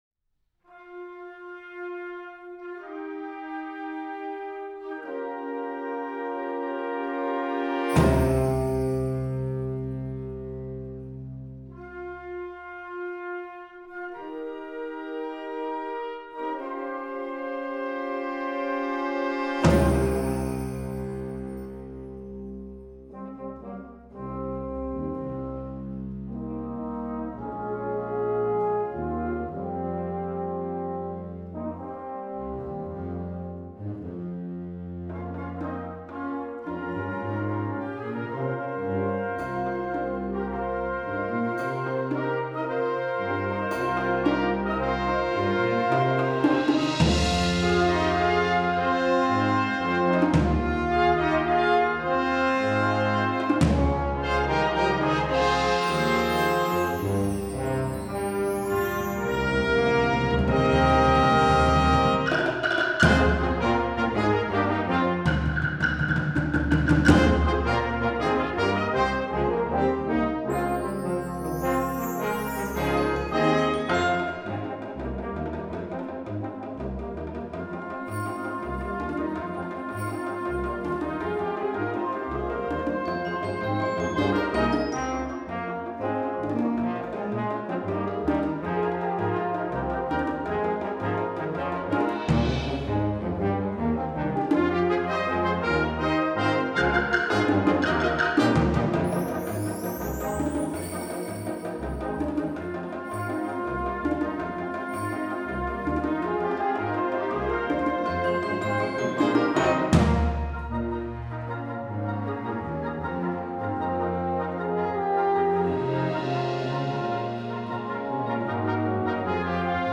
Gattung: für flexibles Ensemble
5:00 Minuten Besetzung: Ensemble gemischt PDF